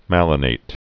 (mălə-nāt, -nĭt)